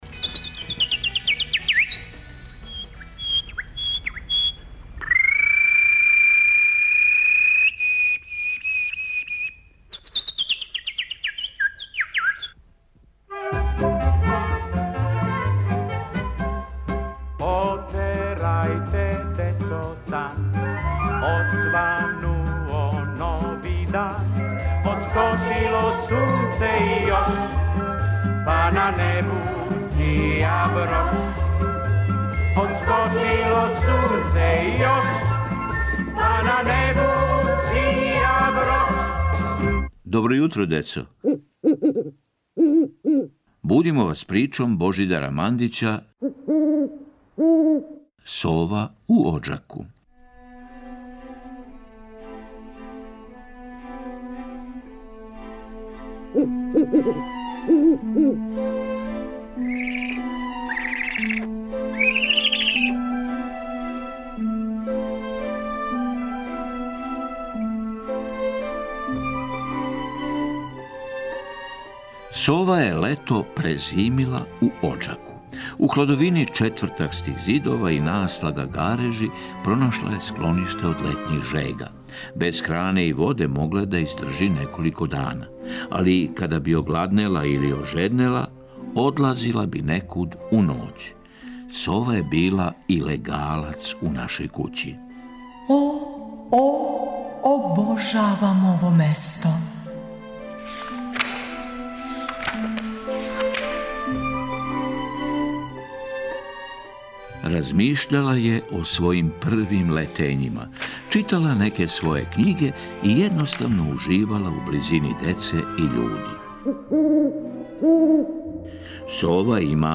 Прича за добро јутро